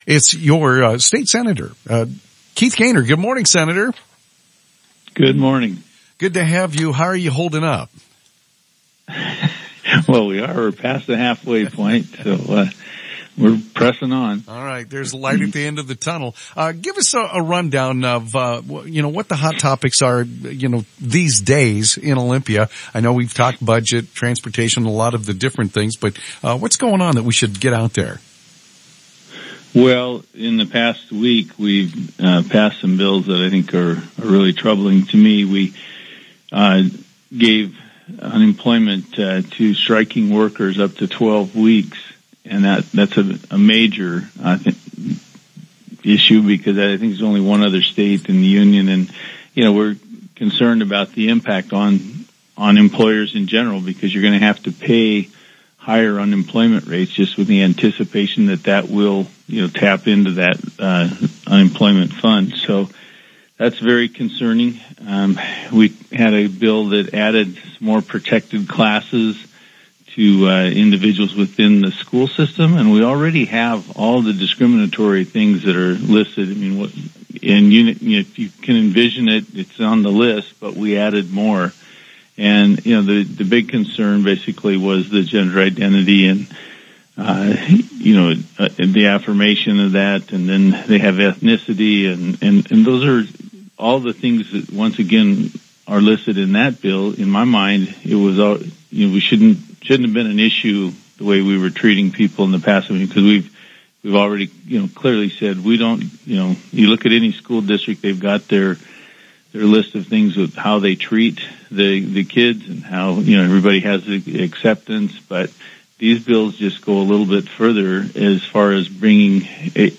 Sen. Keith Goehner tells KOZI Radio he’s concerned about a bill allowing striking workers to get unemployment and early release for juvenile offenders.